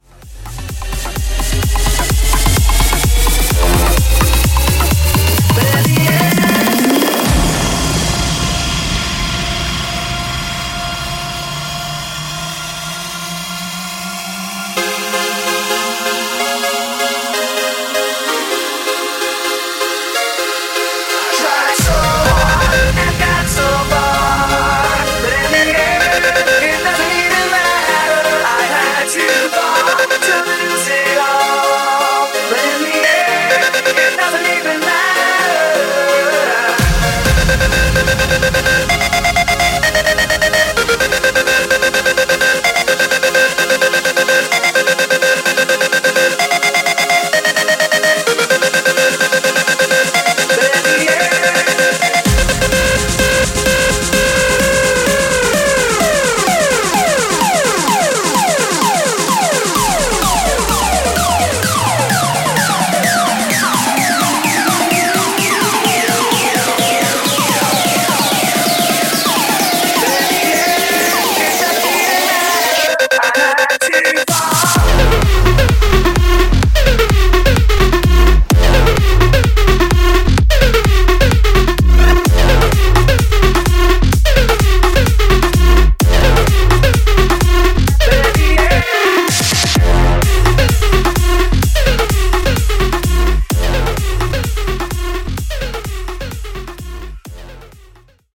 Epic Mashup Edit)Date Added